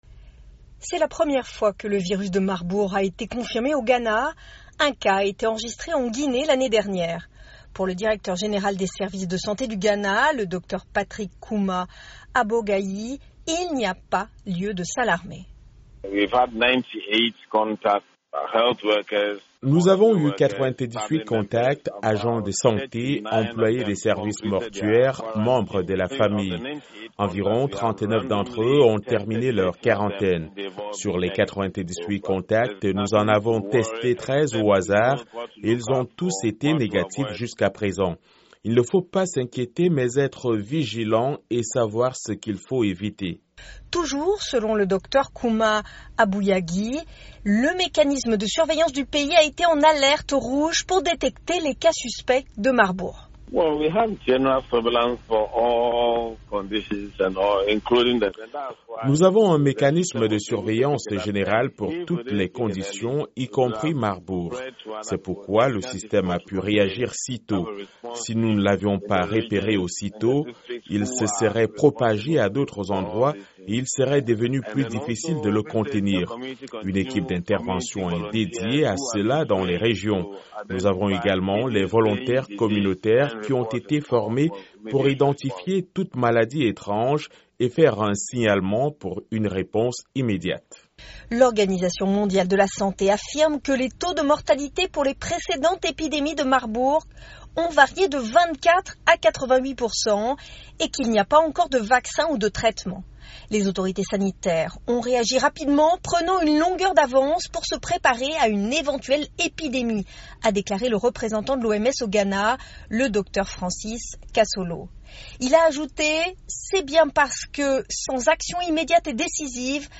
Reportages VOA